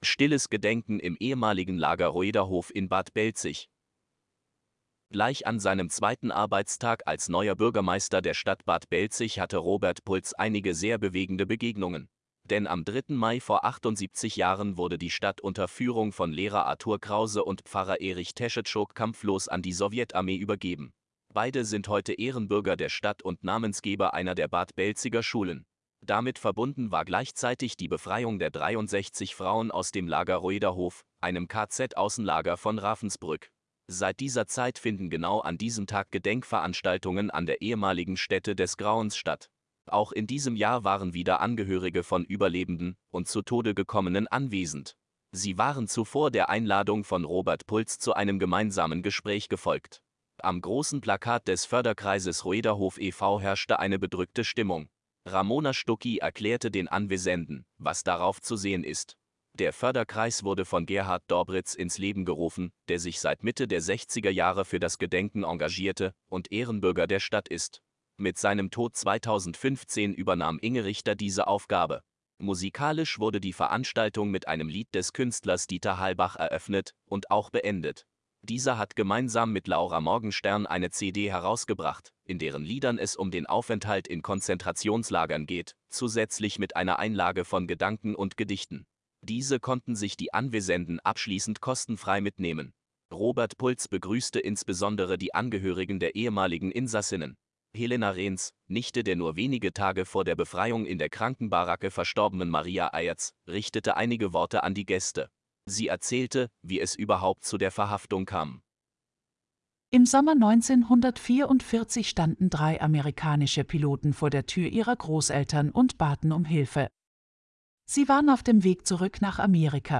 Ab sofort können Sie einige unserer Artikel auch hören. Eine KI macht es möglich.